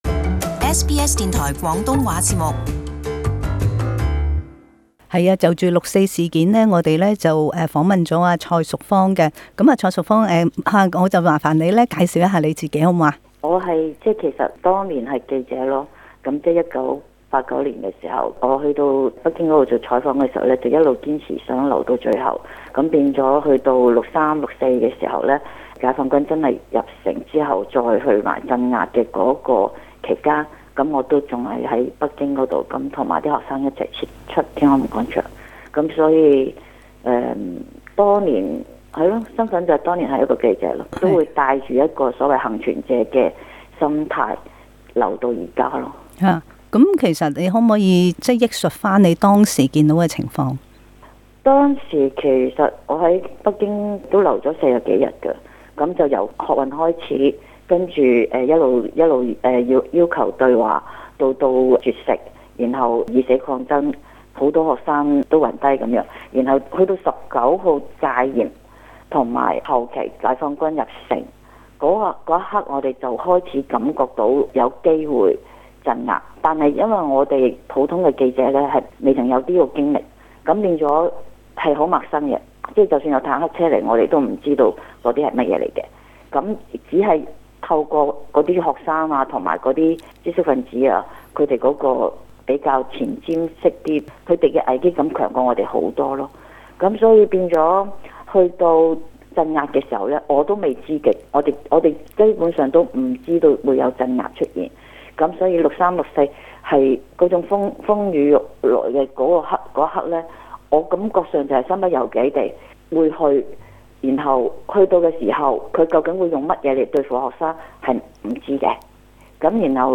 訪問分三節，在第一節，她憶述她當年在天安門採訪到最後留守的情況。